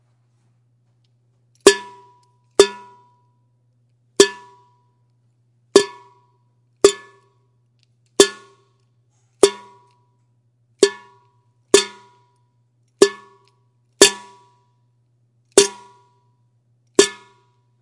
描述：用DS40录音取出垃圾。
Tag: 场记录 垃圾 垃圾